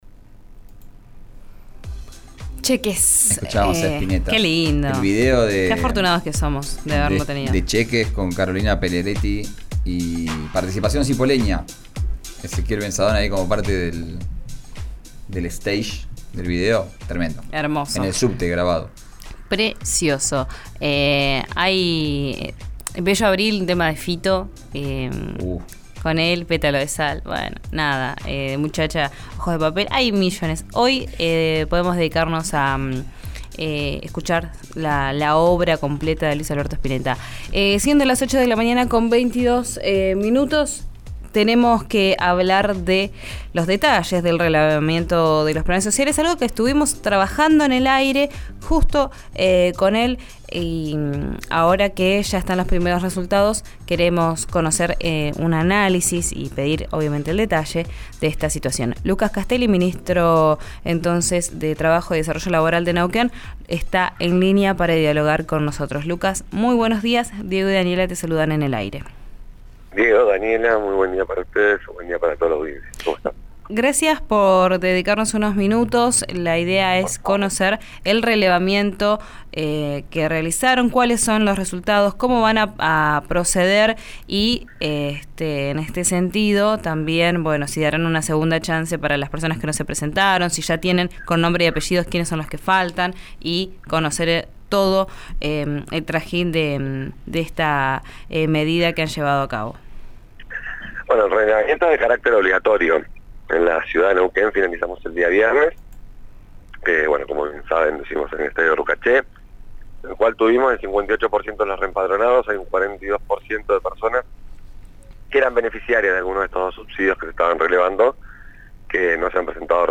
Escuchá la entrevista completa en RÍO NEGRO RADIO.